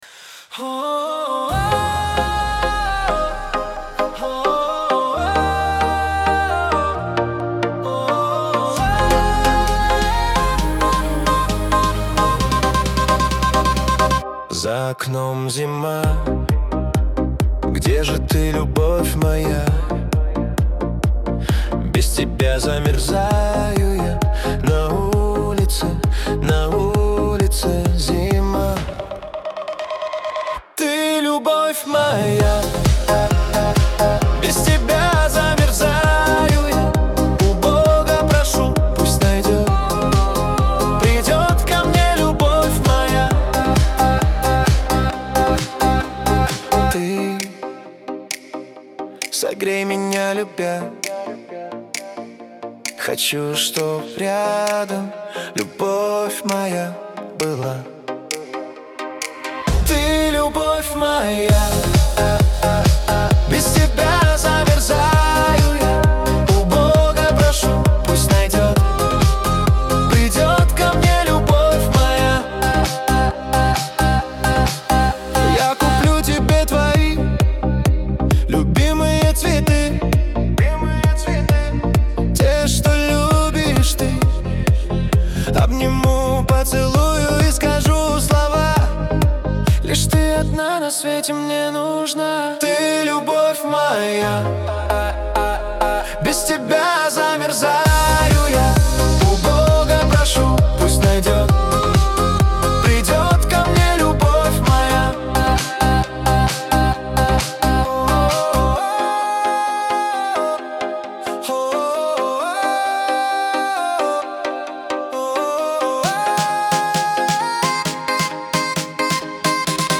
Шансон
Лирика , диско